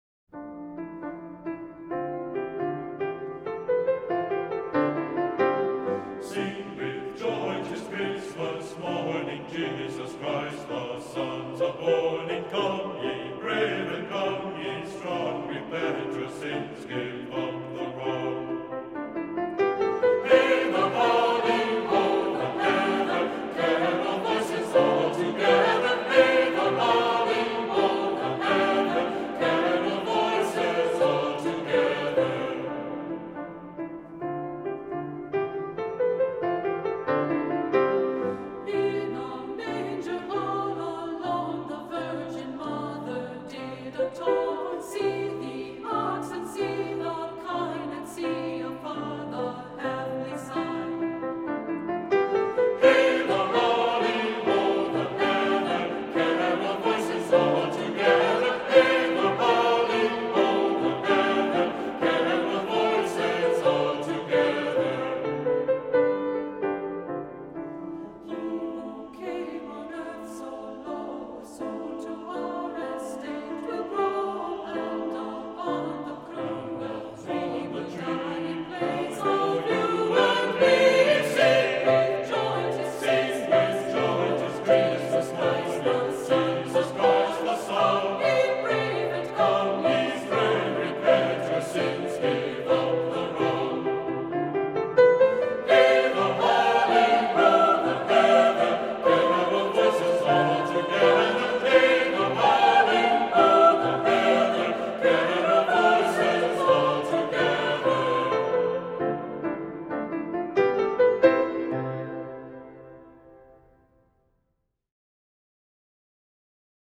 Voicing: Unison with descant; Two-part equal; Two-part mixed